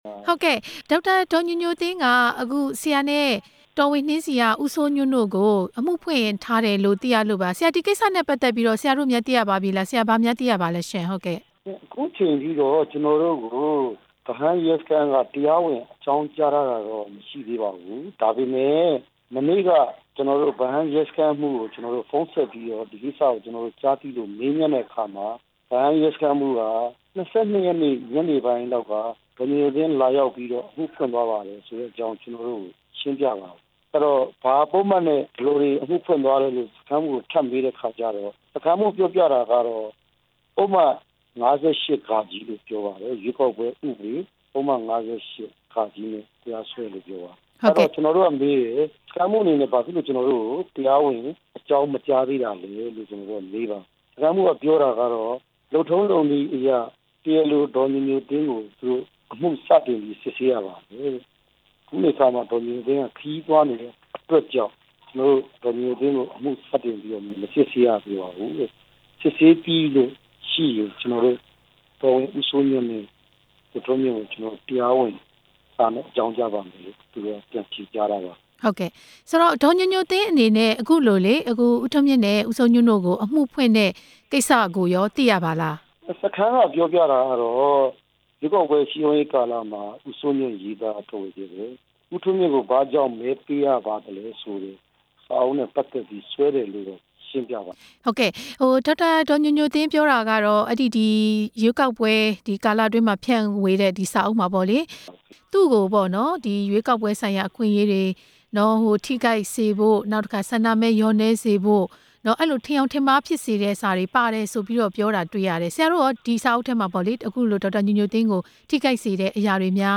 NLD ကိုယ်စားလှယ် ဦးထွန်းမြင့်ကို မေးမြန်းချက်